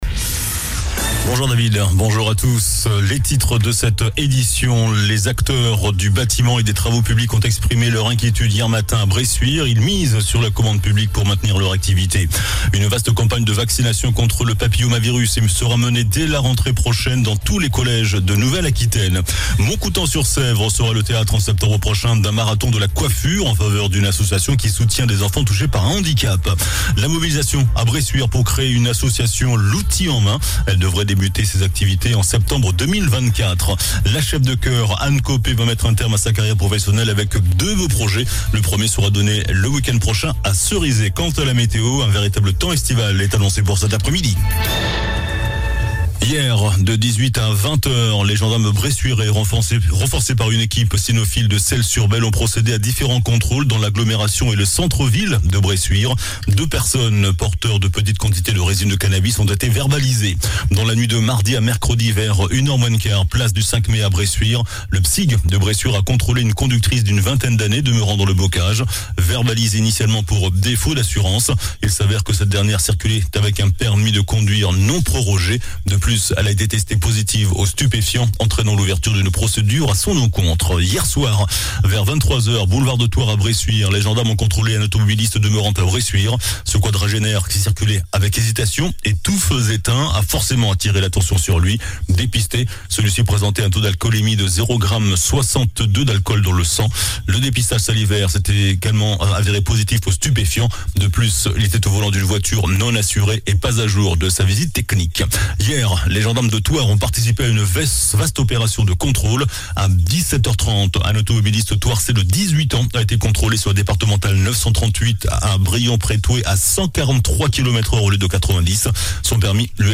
JOURNAL DU MERCREDI 14 JUIN ( MIDI )